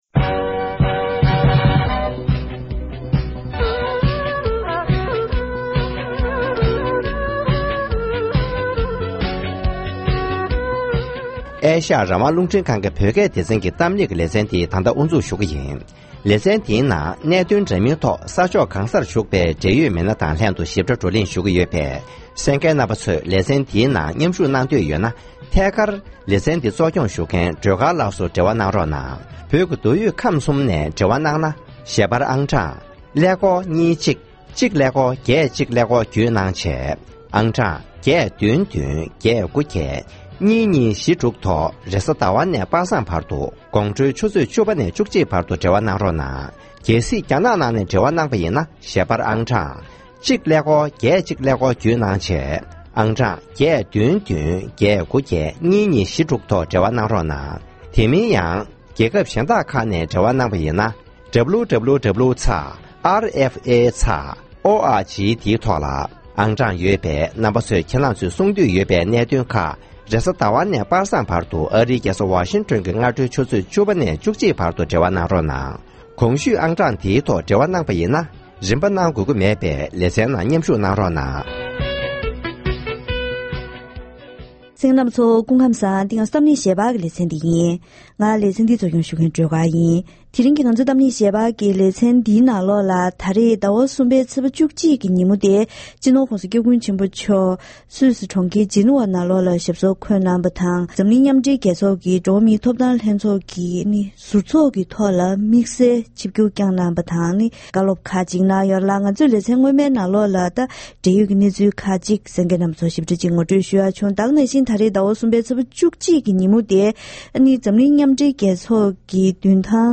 ༸གོང་ས་༸སྐྱབས་མགོན་ཆེན་པོ་མཆོག་ནས་སུད་སི་ནང་ཡོད་པའི་བོད་མི་སུམ་སྟོང་ཙམ་ལ་མཇལ་ཁ་དང་བཀའ་སློབ་གནང་བ།